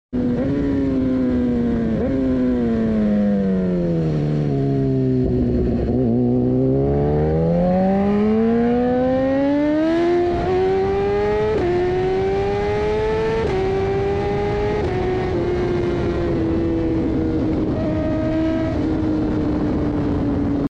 BMW S1000 RR 🇩🇪🦈 Sound Effects Free Download